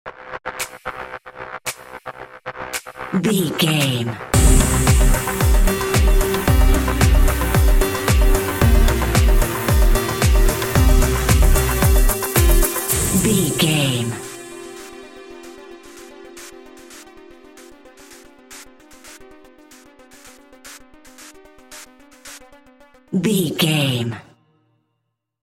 Aeolian/Minor
groovy
uplifting
driving
energetic
repetitive
synthesiser
drum machine
house
techno
synth bass
upbeat